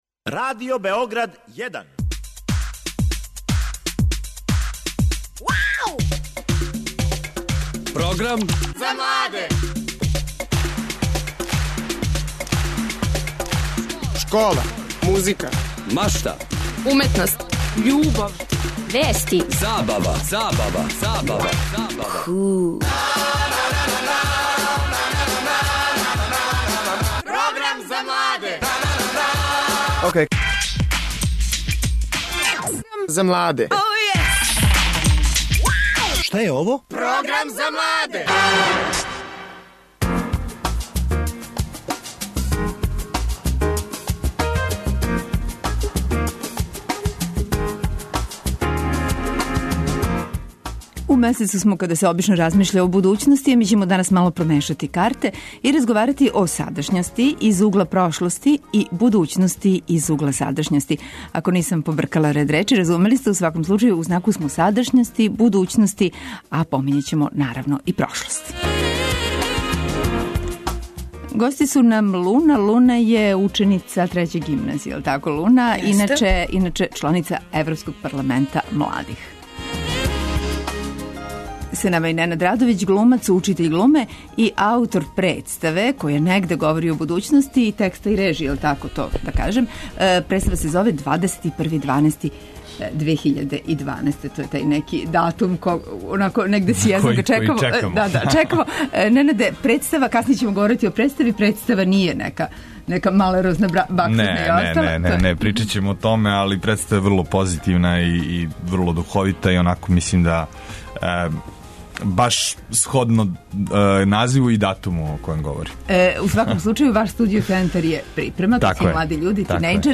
Наши гости тинејџери говориће о почетку другог полугодишта и ономе што их очекује у блиској будућности.